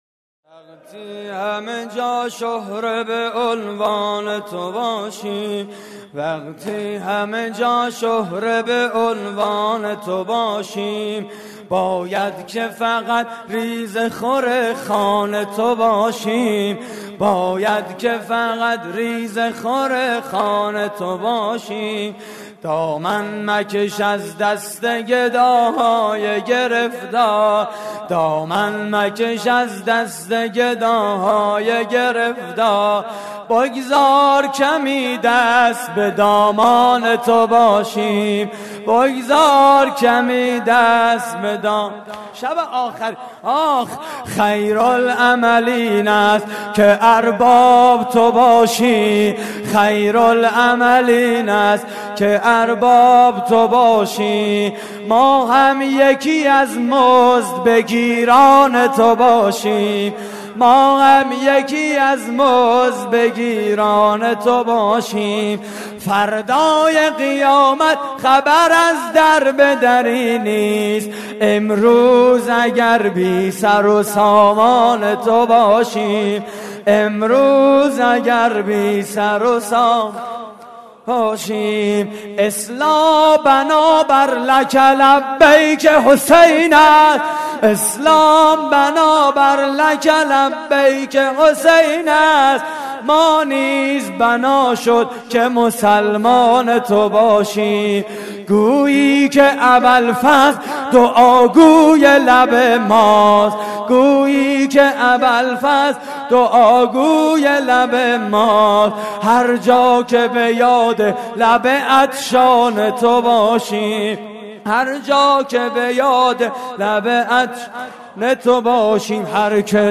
مراسم عزاداری شهادت امام سجاد (ع) / هیئت الزهرا (س) – دانشگاه صنعتی شریف؛